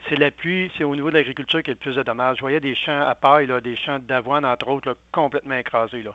Le préfet de la MRC de Bécancour qui est aussi agriculteur, Mario Lyonnais, est revenu sur ce qu’il a malheureusement pu constater dans la région.